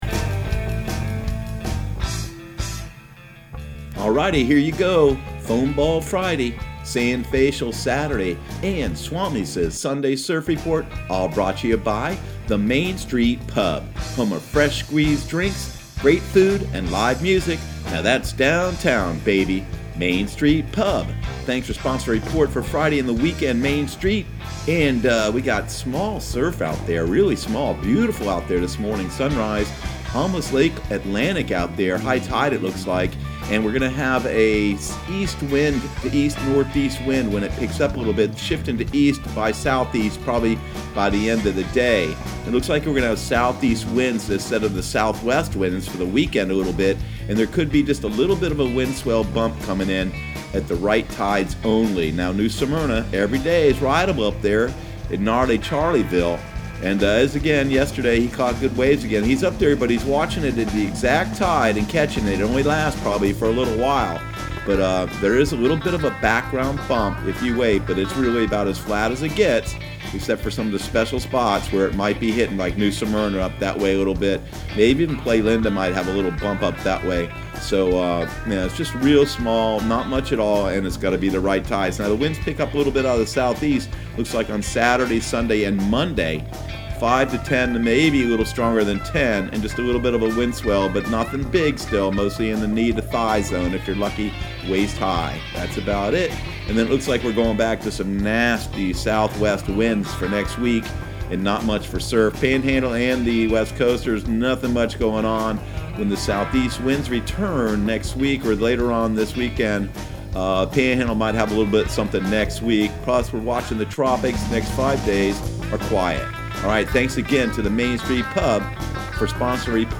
Surf Guru Surf Report and Forecast 06/14/2019 Audio surf report and surf forecast on June 14 for Central Florida and the Southeast.